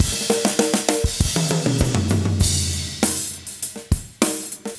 16 rhdrm100snare.wav